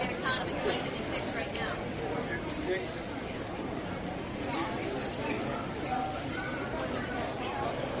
Airplane Flyby
# airplane # flyby # jet About this sound Airplane Flyby is a free sfx sound effect available for download in MP3 format.
360_airplane_flyby.mp3